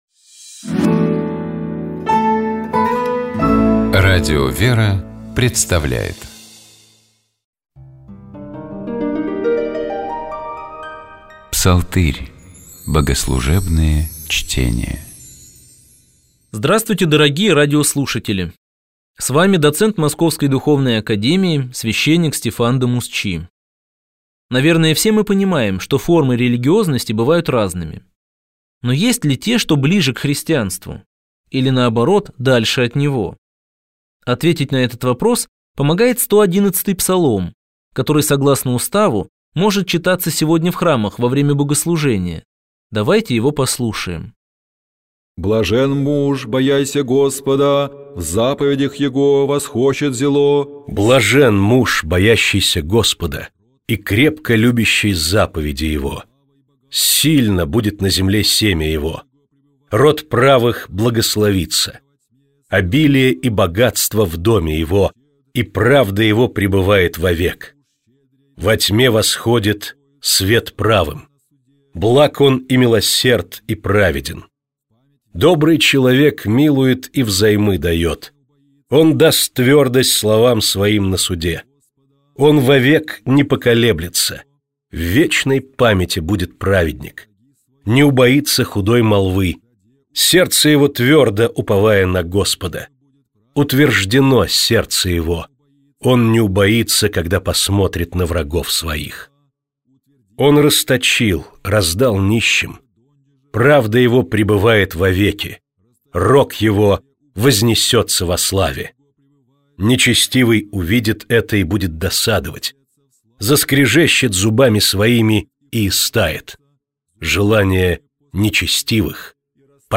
Псалтирь: богослужебные чтения